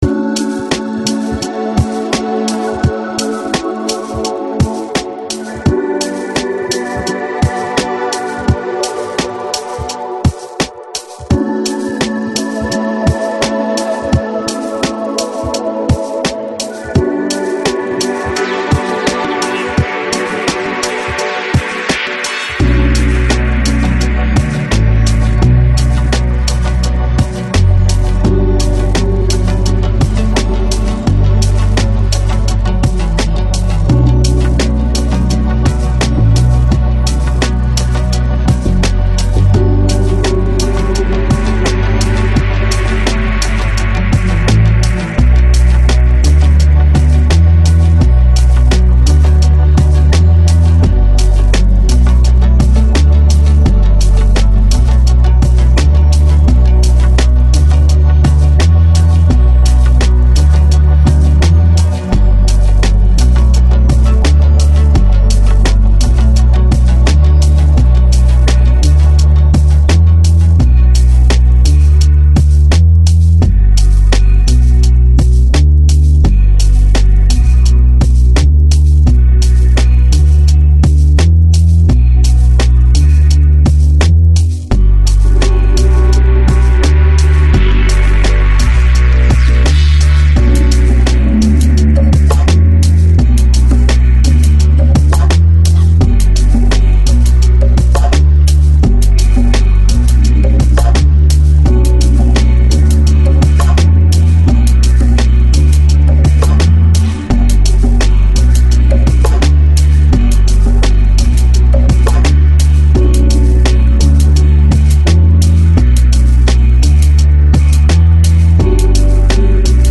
Lo-Fi | Lounge | Chillout | Nu Jazz